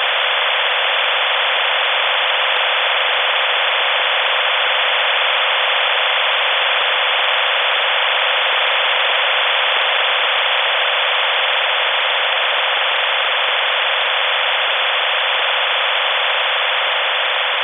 Russian 128-tone OFDM waveform with 6kHz BW
OFDM-128_tfc.WAV